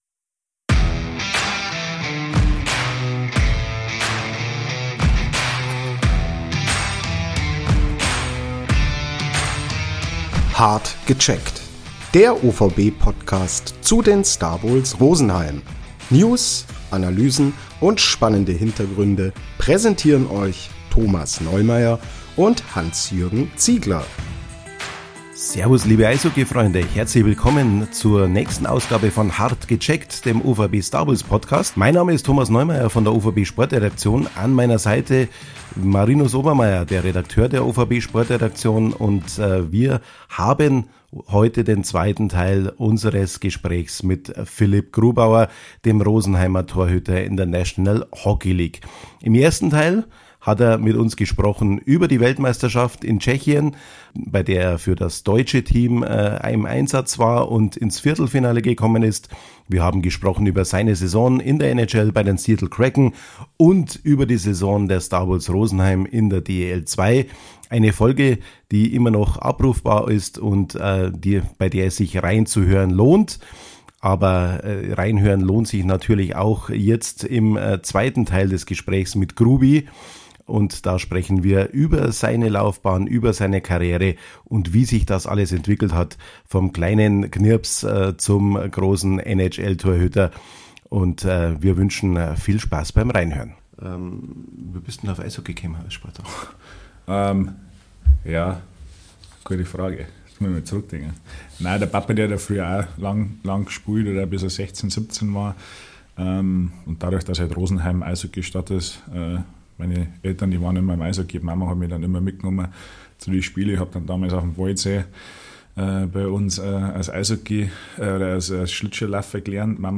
Er ist Stanley Cup-Sieger, Stammtorwart in der National Hockey League (NHL), Nationaltorhüter und in der 35. Folge Gast im OVB-Podcast „Hart gecheckt“: Philipp Grubauer.